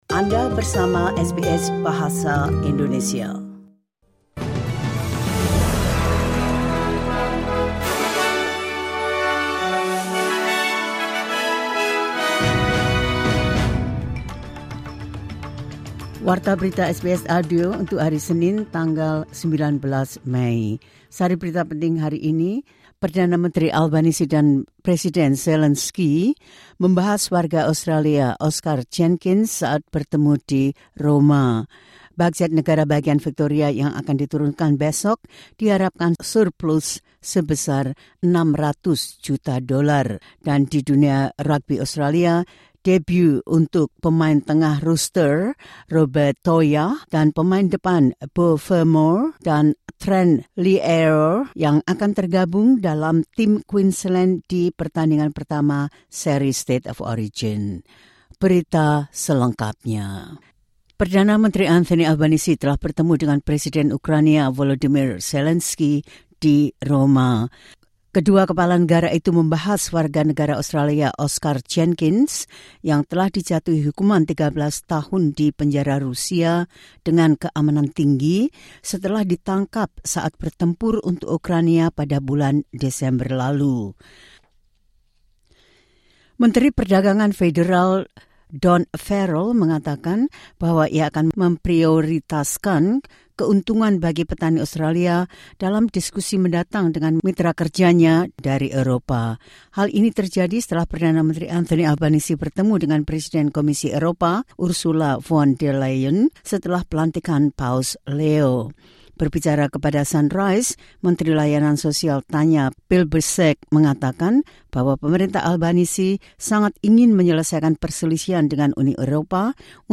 The latest news SBS Audio Indonesian Program – 19 May 2025.